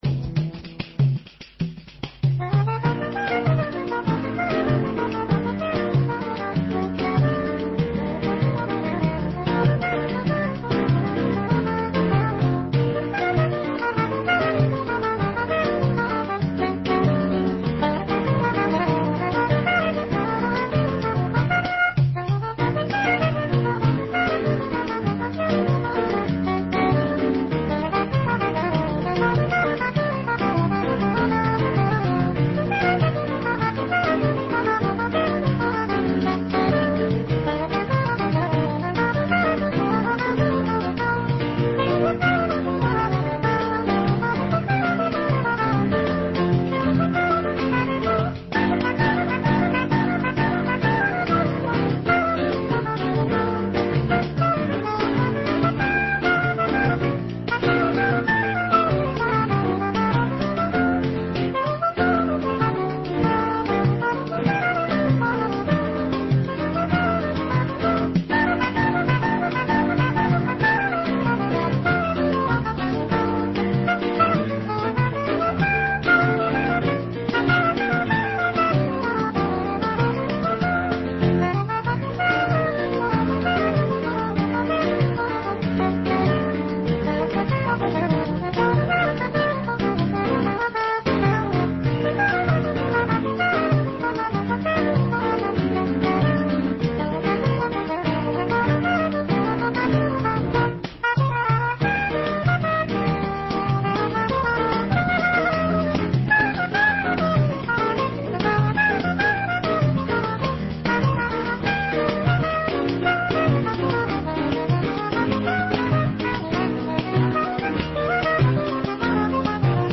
trío instrumental
música brasileña de los años que van entre los 20´s y 70´s